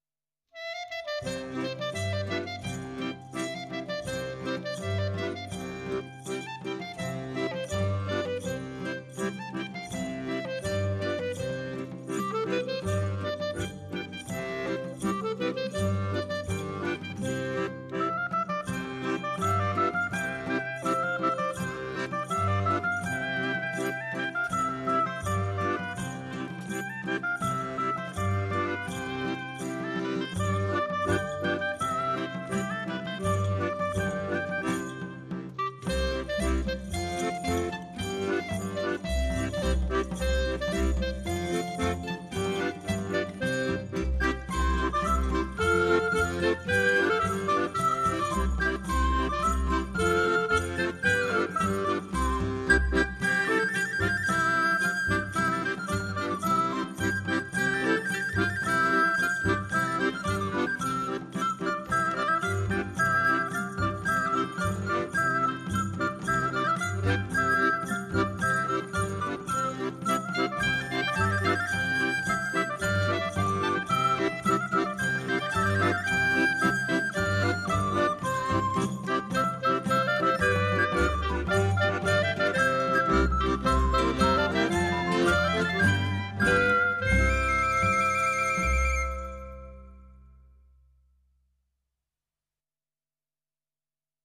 DANSES CASTANYADA